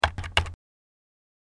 جلوه های صوتی
دانلود صدای کیبورد 7 از ساعد نیوز با لینک مستقیم و کیفیت بالا